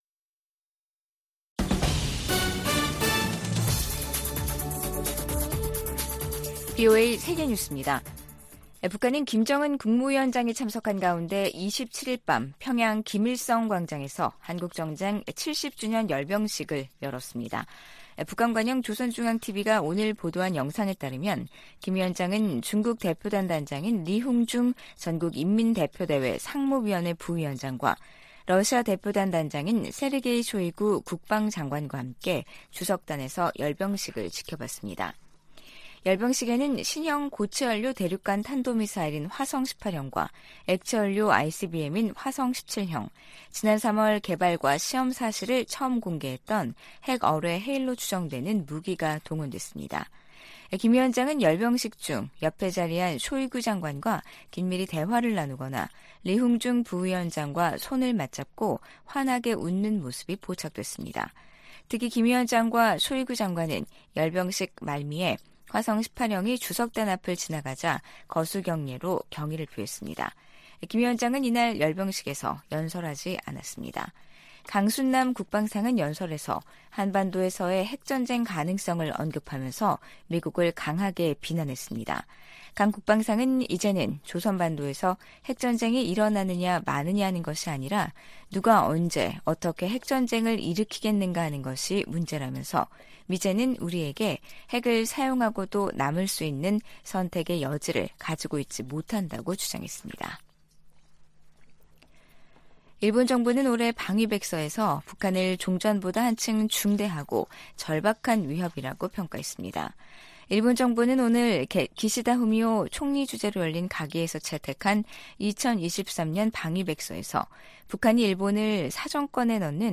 VOA 한국어 간판 뉴스 프로그램 '뉴스 투데이', 2023년 7월 28일 3부 방송입니다. 미 국무부는 러시아가 북한의 불법 무기 프로그램을 지원하고 있다고 비난했습니다. 로이드 오스틴 미 국방장관은 미한 상호방위조약이 체결된 지 70년이 지난 지금 동맹은 어느 때보다 강력하다고 밝혔습니다. 북한은 김정은 국무위원장이 참석한 가운데 '전승절' 열병식을 열고 대륙간탄도미사일 등 핵 무력을 과시했습니다.